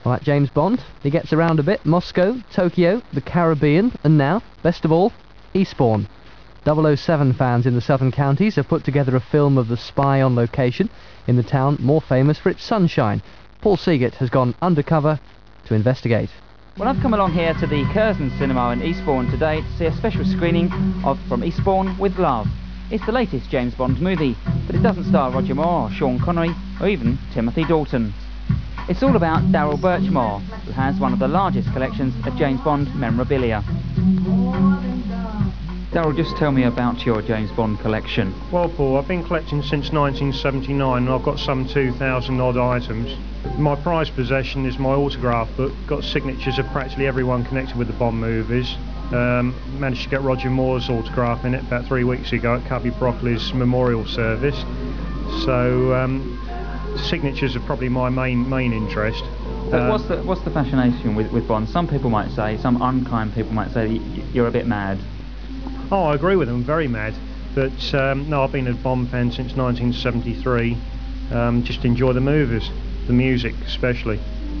Radio.wav